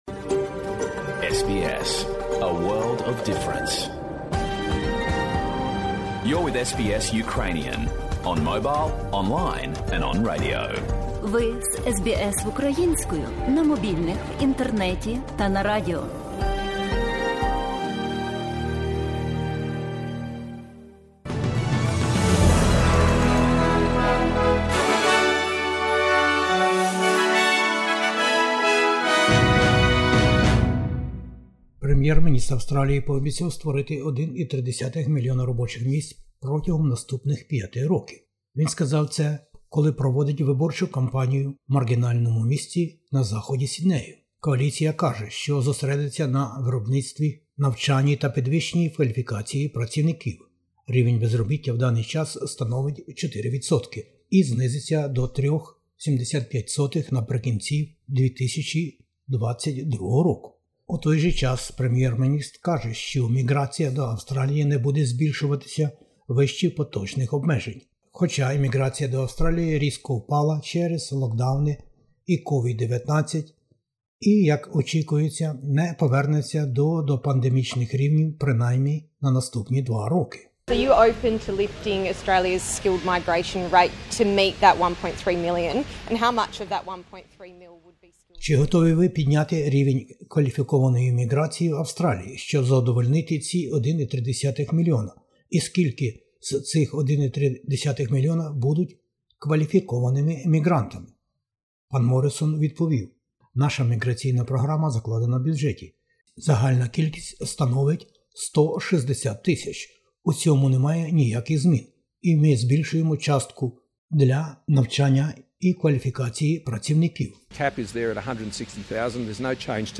Бюлетень SBS новин українською.